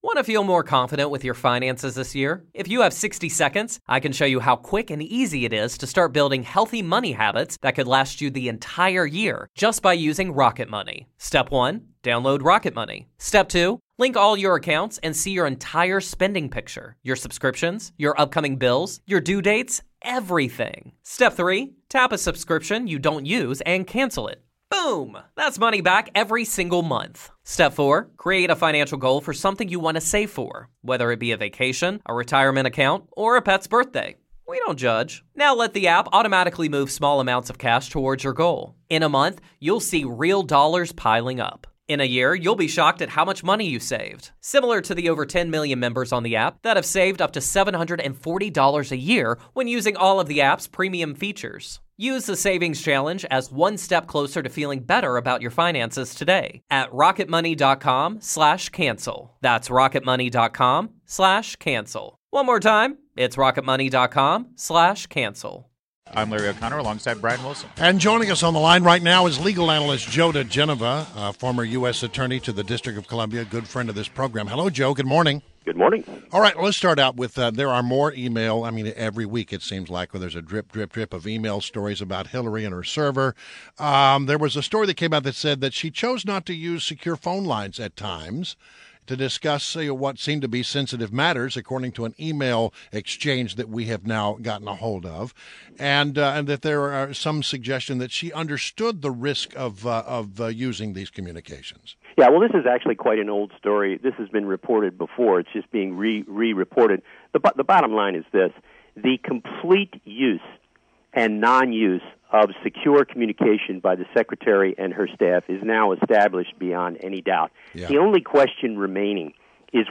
WMAL Interview: Joe diGenova 05.16.16